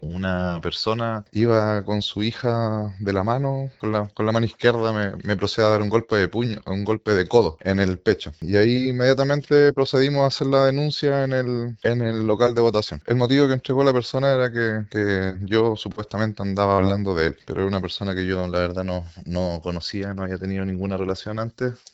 En conversación con La Radio, Felipe Bustos indicó que una persona que iba con su hija se acercó a él y lo golpeó con su codo en el pecho, lo que habría sido motivado por dichos anteriores, pero recalcó que no conoce a su agresor.